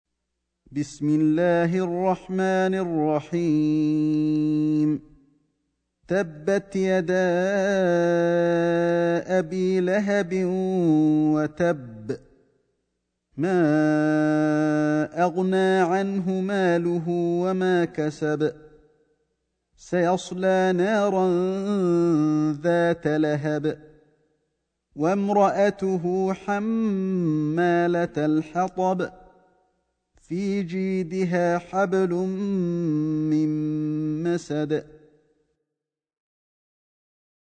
سورة المسد > مصحف الشيخ علي الحذيفي ( رواية شعبة عن عاصم ) > المصحف - تلاوات الحرمين